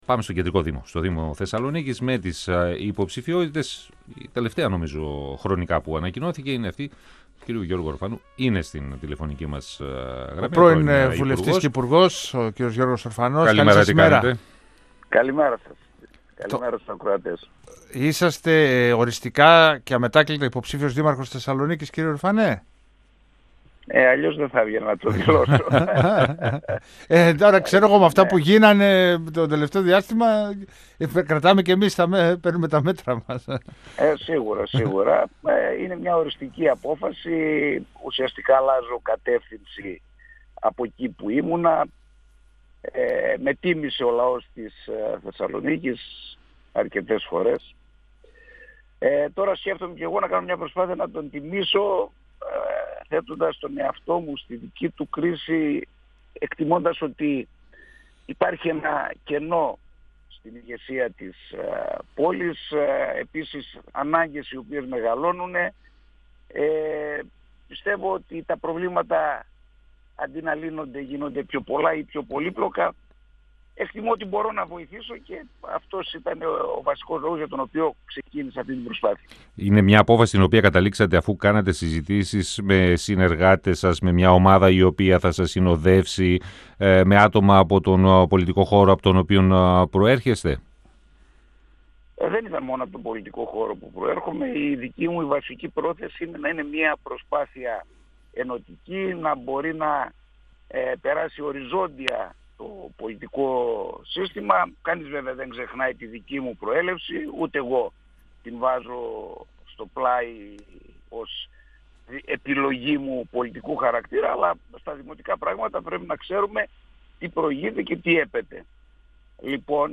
O πρώην υπουργός και βουλευτής της Νέας Δημοκρατίας, Γιώργος Ορφανός, στον 102FM του Ρ.Σ.Μ. της ΕΡΤ3